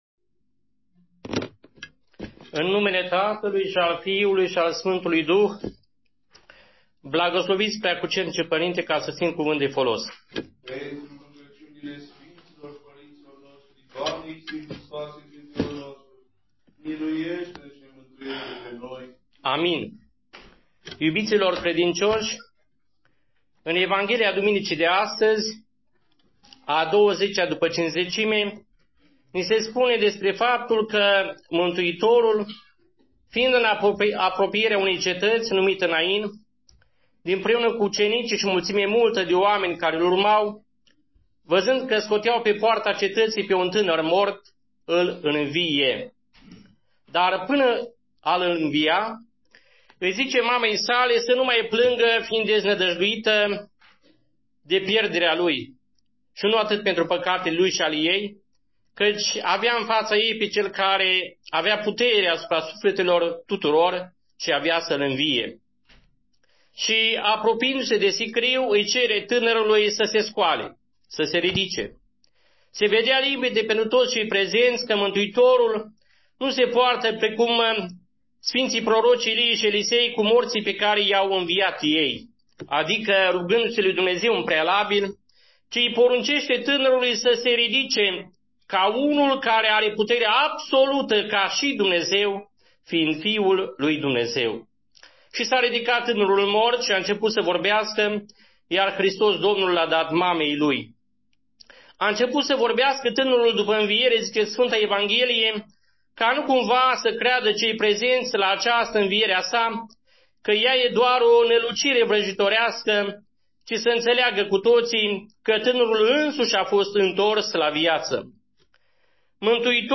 Luca VII, 11-16 . Ascultați predica aici